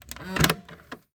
box_open.wav